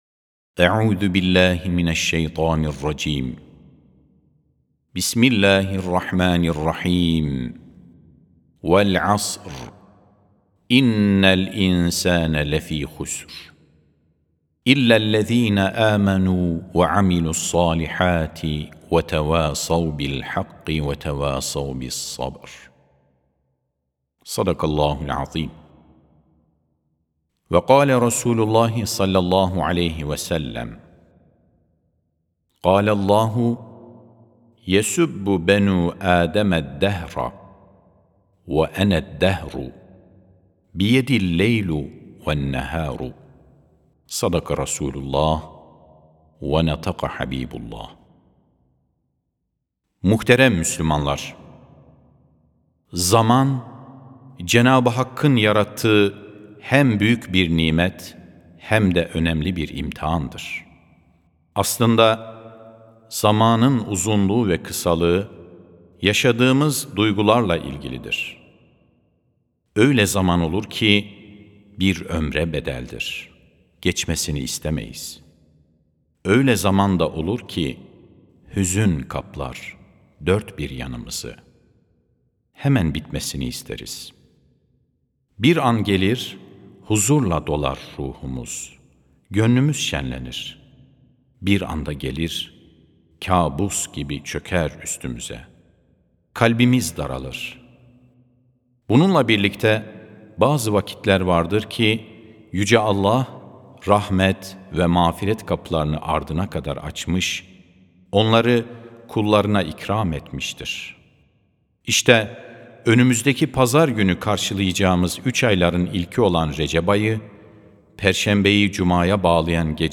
19.12.2025 Cuma Hutbesi: Her Anımızı Ebedi Kazanca Dönüştürebiliriz (Sesli Hutbe, Türkçe, İngilizce, İspanyolca, İtalyanca, Almanca, Rusça, Fransızca, Arapça)
Sesli Hutbe (Her Anımızı Ebedi Kazanca Dönüştürebiliriz).mp3